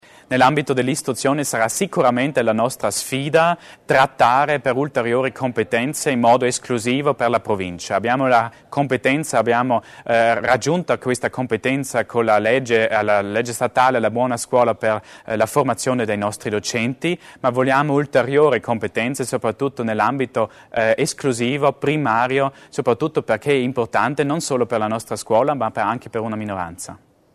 L'Assessore Achammer spiega gli obiettivi in tema di formazione